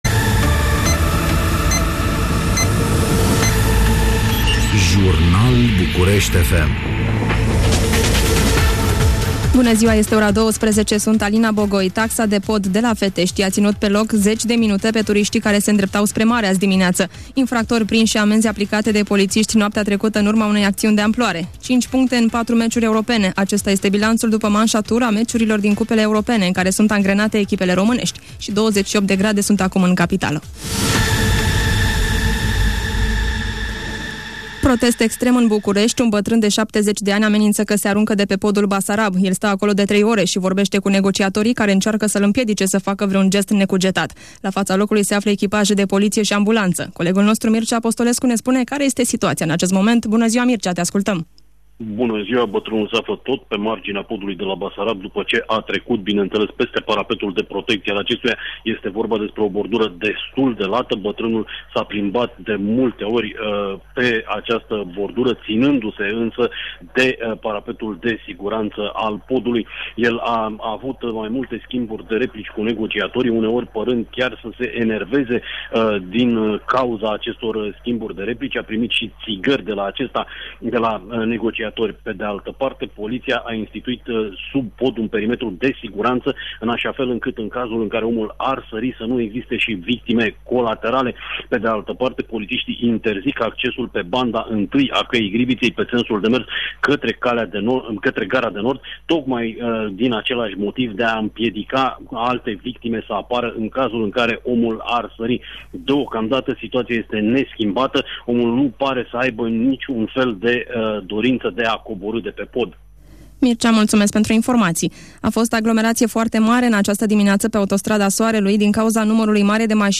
AUDIO: JURNAL ORA 12